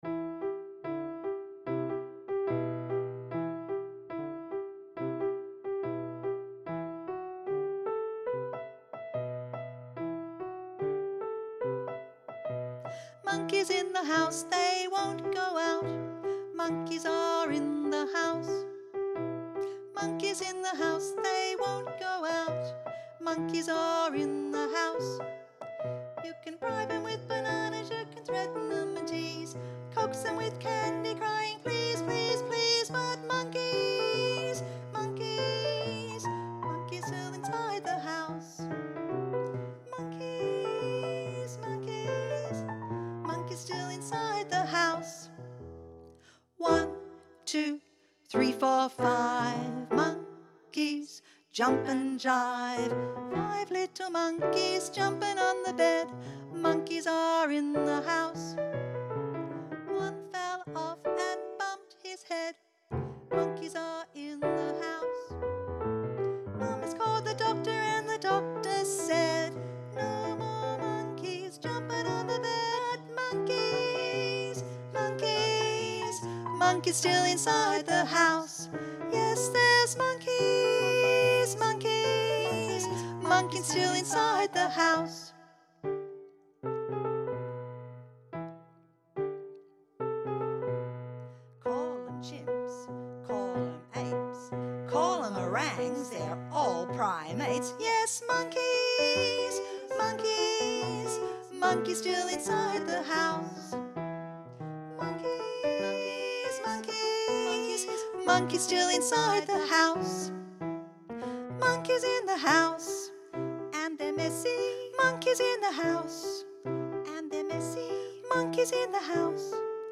Vox Populi Choir is a community choir based in Carlton and open to all comers.
Arranged & Adapted by Cyndee Giebler